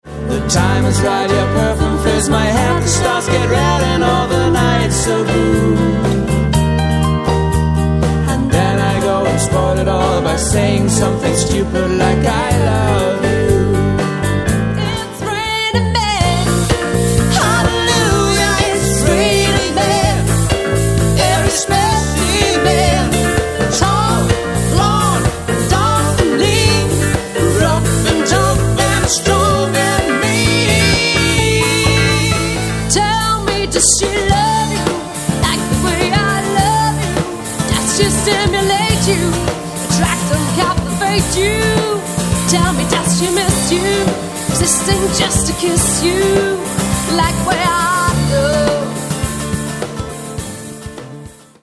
Die ideale Besetzung für Tanzveranstaltungen.